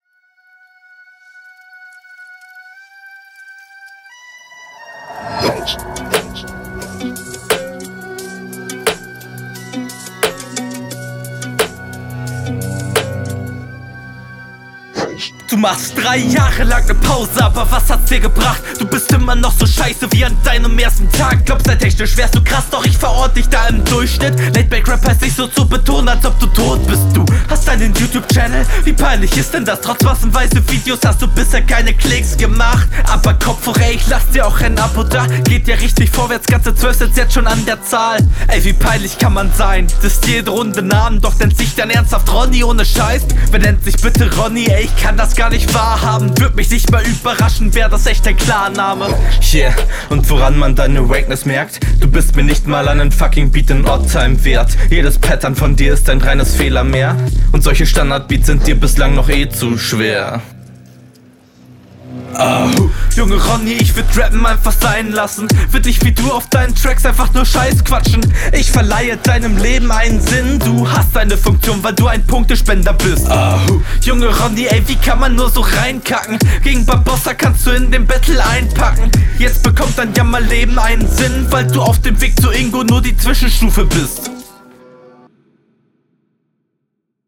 Crazy Beat.
Manchmal leidet aber die Verständlichkeit.